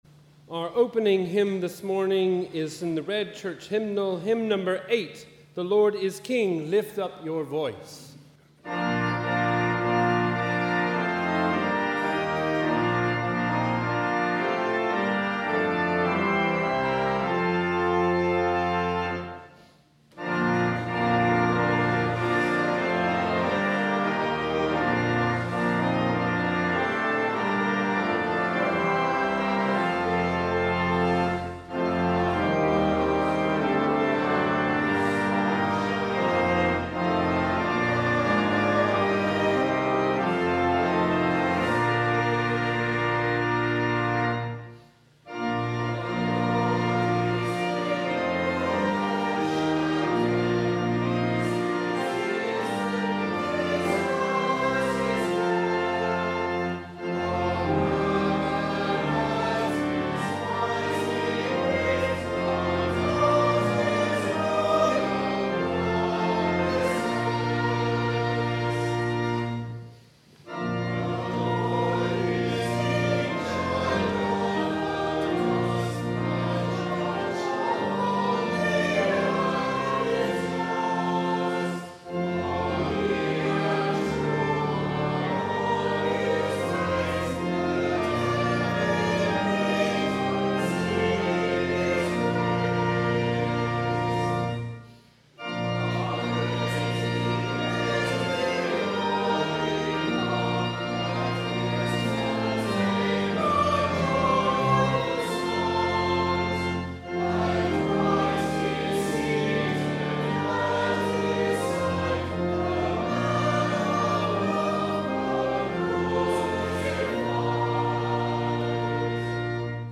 Wherever you are, we warmly welcome you to our service of Morning Prayer on the 10th Sunday after Trinity.